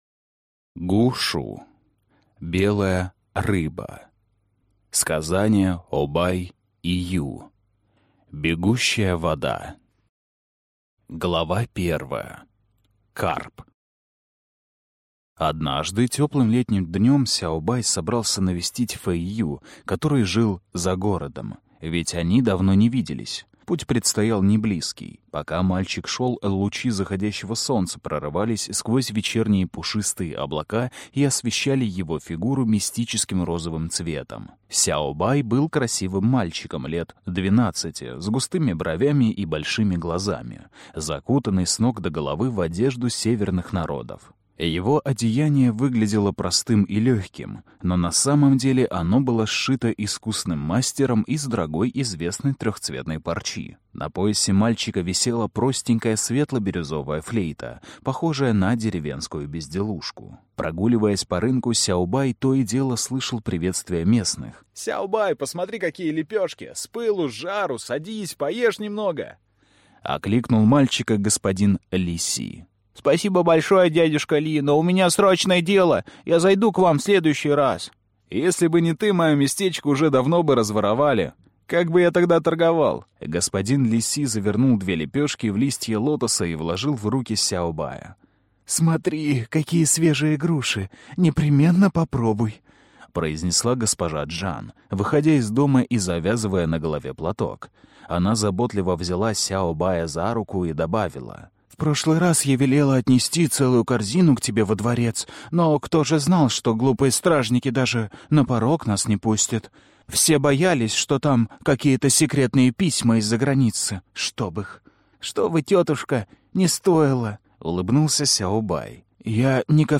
Аудиокнига Белая рыба. Сказания о Бай и Ю. Бегущая вода | Библиотека аудиокниг